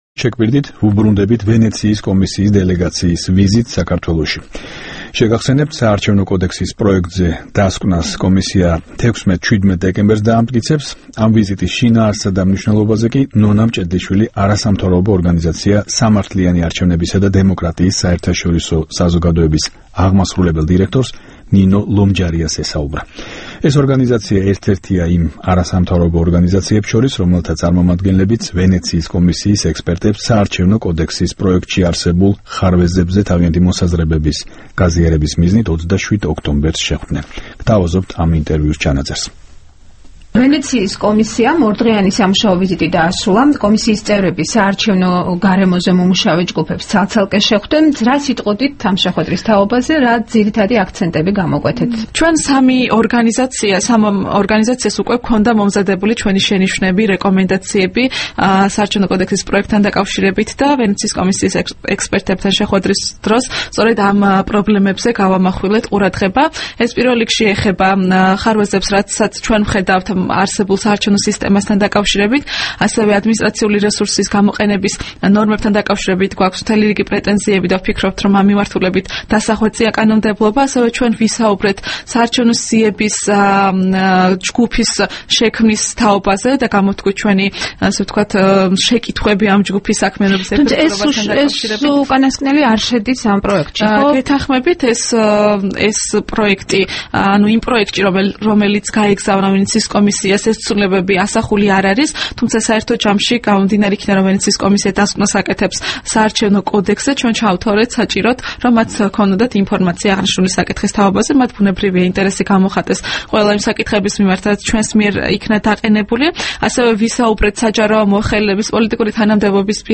ინტერვიუ